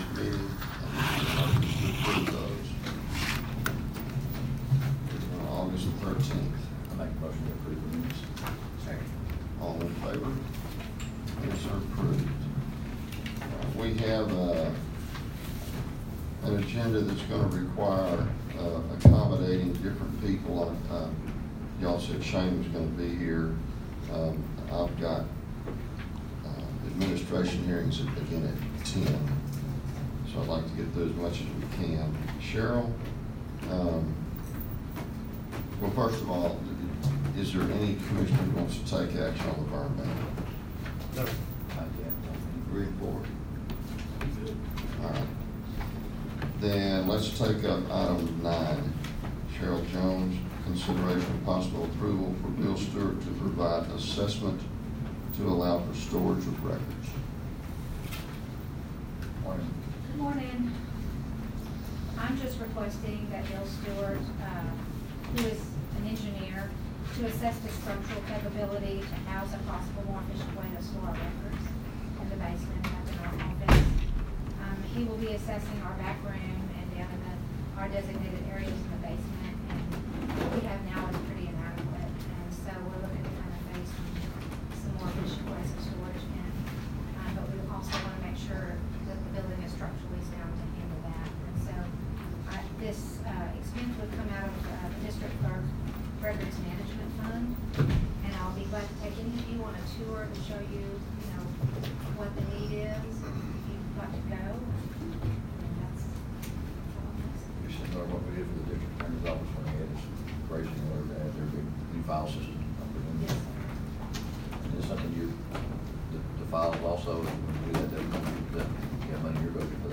Aug-20-Commissioners.m4a